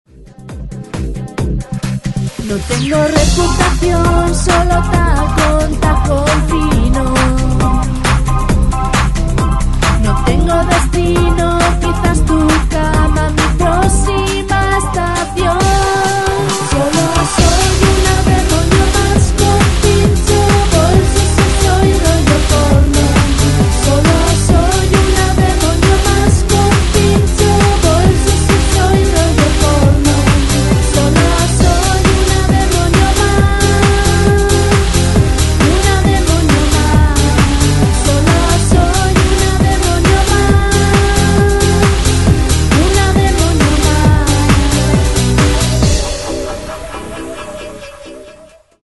convirtiéndola en una pista perfecta para bailar.